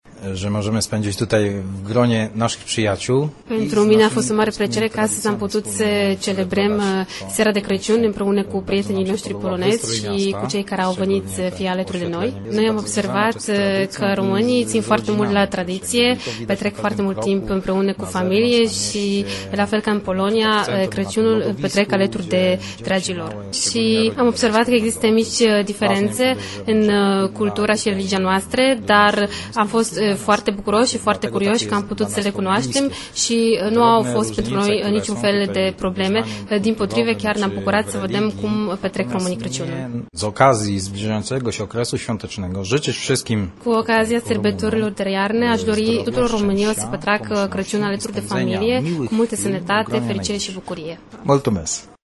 Seară de Crăciun la Centrul Cultural Polonez din Craiova